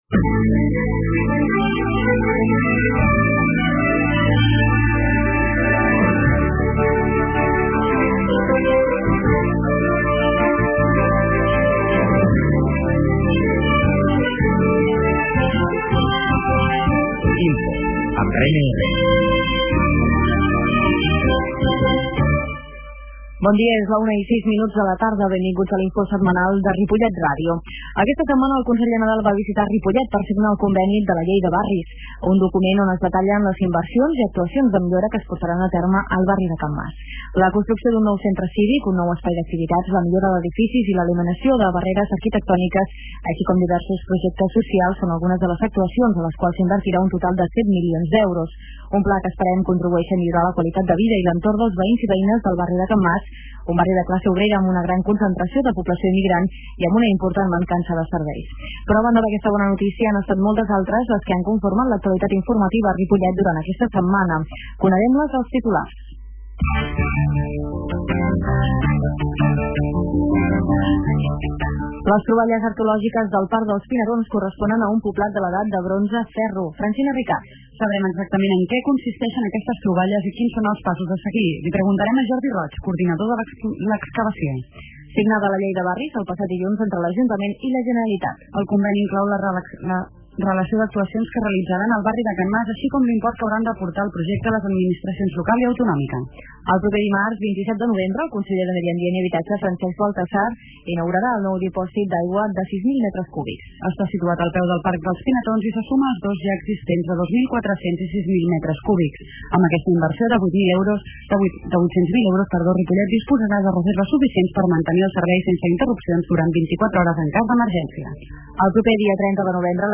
Escolteu en directe per la ràdio o la xarxa el resum de notícies de Ripollet Ràdio (91.3 FM), que s'emet en directe a les 13 hores.
Aquesta setmana amb l'entrevista a la regidora de Serveis Socials de l'Ajuntament de Ripollet, Maria Lladó.
La qualitat de sò ha estat reduïda per tal d'agilitar la seva descàrrega.